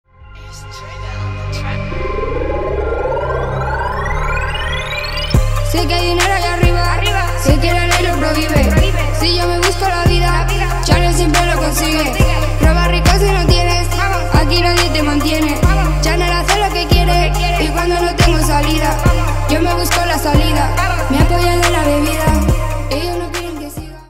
• Качество: 320, Stereo
женский вокал
Хип-хоп
Trap
качающие
Bass